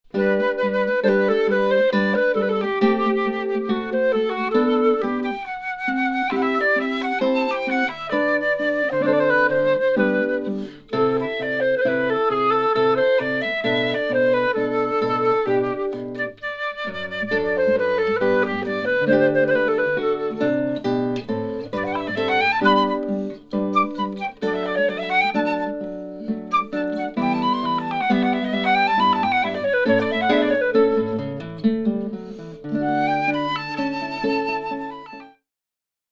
• Beyer MCE93 cardioid condenser microphones
flute/guitar
replayed trough speakers L and R with only the natural
acoustics of the listening room added.
I notice that it is more relaxing to listen and that the sound is no longer inside my head (1).